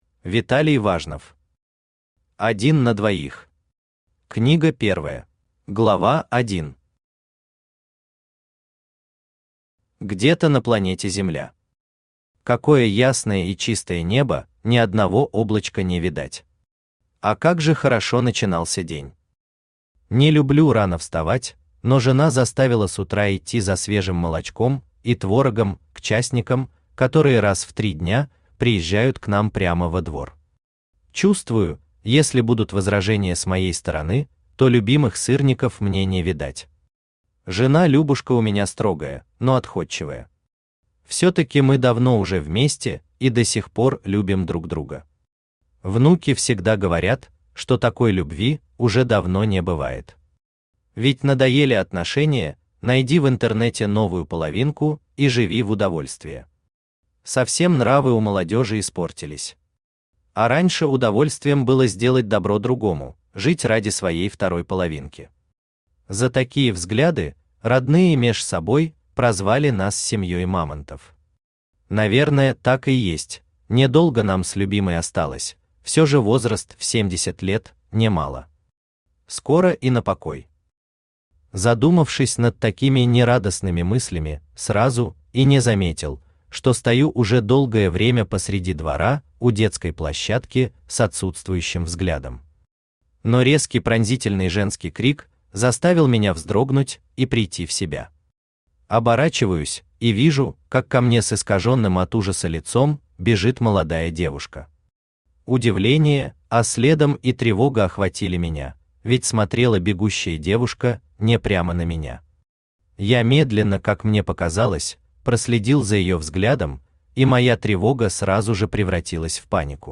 Аудиокнига Один на двоих. Книга первая | Библиотека аудиокниг
Книга первая Автор Виталий Геннадьевич Важнов Читает аудиокнигу Авточтец ЛитРес.